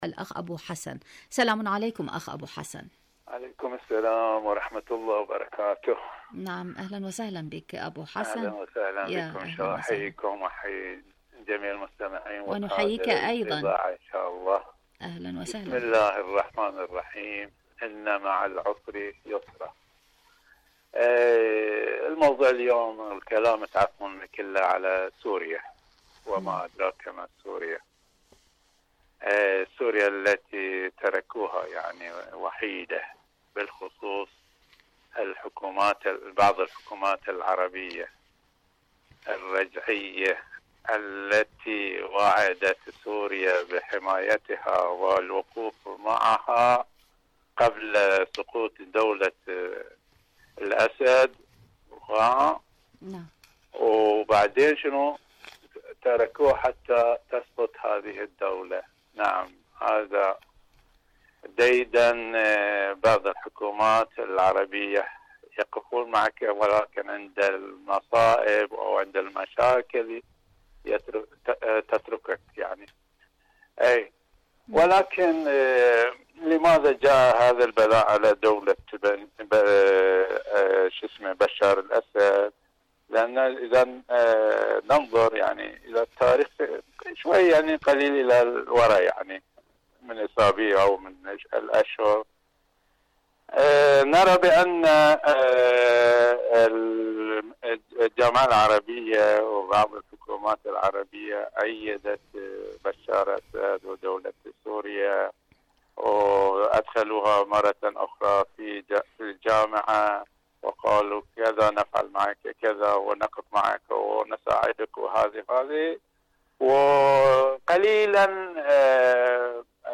إذاعة طهران- المنتدى الإذاعي